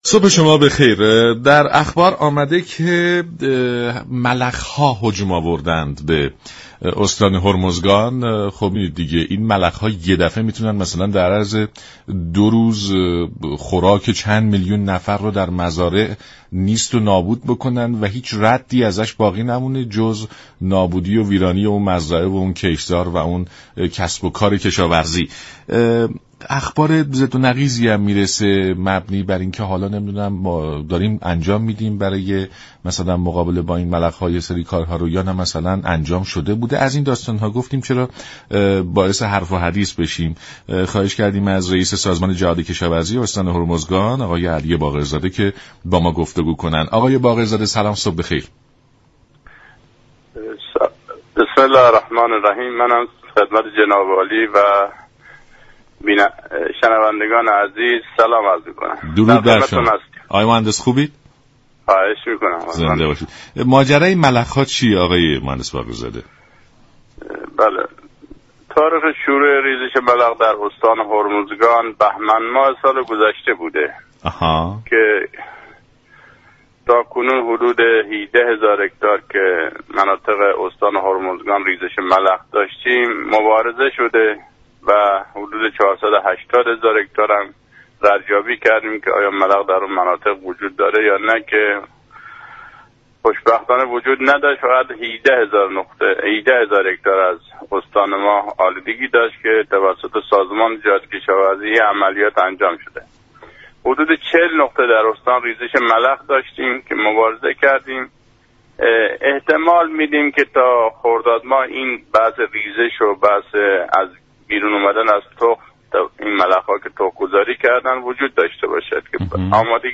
سازمان جهاد كشاورزی استان هرمزگان برای مقابله با این پدیده به بودجه ی 5 میلیارد تومان نیازمند است. برنامه سلام صبح بخیر رادیو ایران شنبه تا پنجشنبه ساعت 6:35 از رادیو ایران پخش می شود. این گفت و گو در ادامه باهم می شنویم.